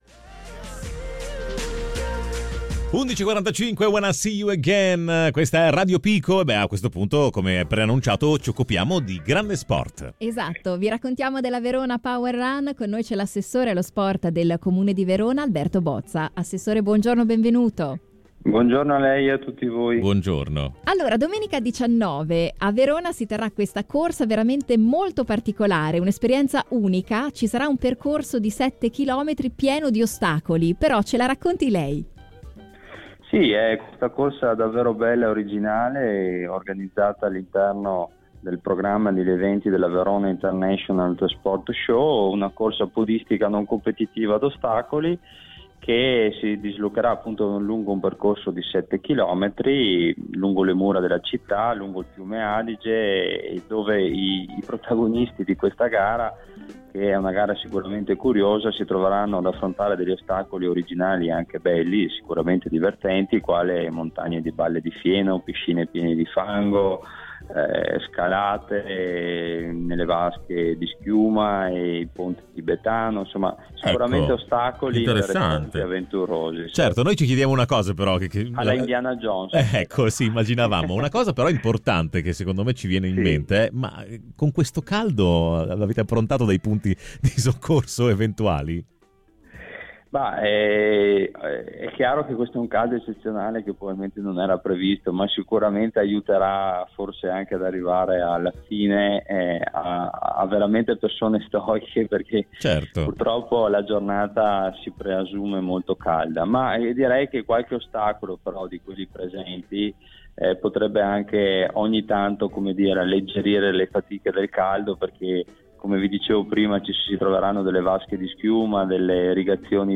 Sentiamo i particolari dalla voce dell’assessore allo Sport del Comune di Verona Alberto Bozza.